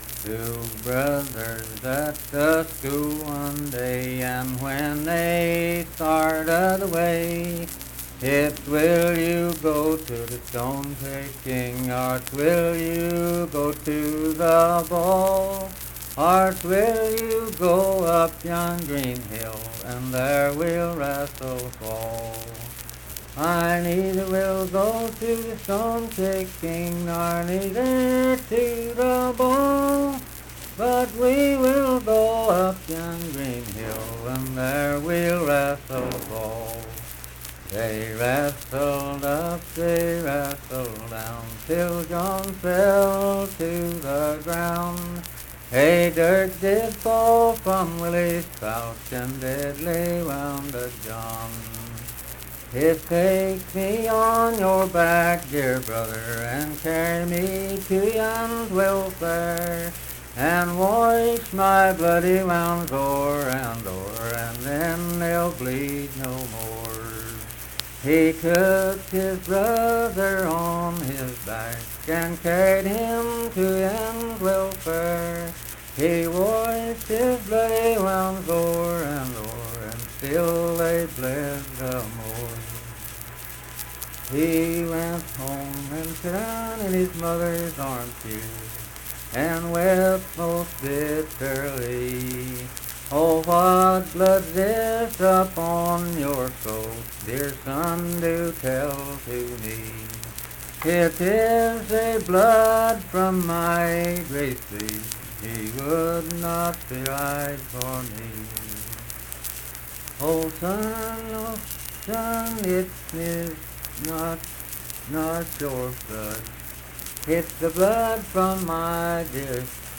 Unaccompanied vocal music performance
Performed in Kliny, Pendleton County, WV.
Voice (sung)